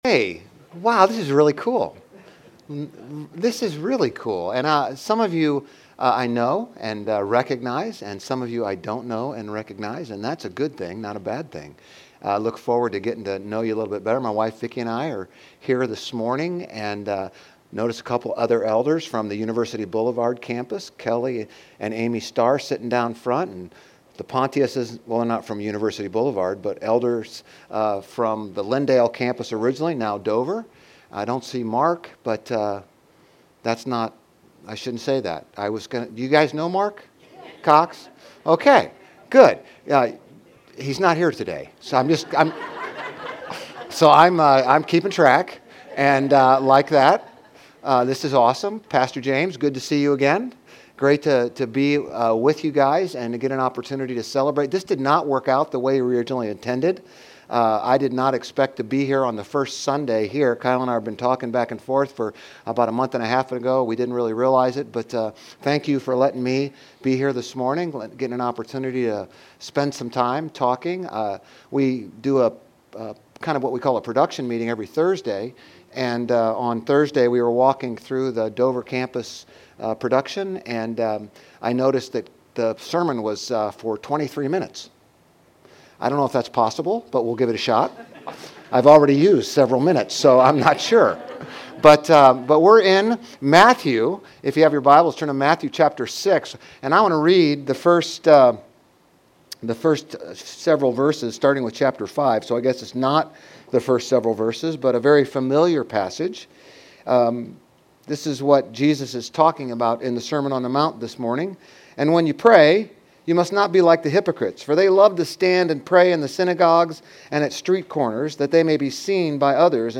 Grace Community Church Dover Campus Sermons 3_9 Dover Campus Mar 10 2025 | 00:34:15 1x 00:00 / 00:34:15 Subscribe Share RSS Feed Share Link Embed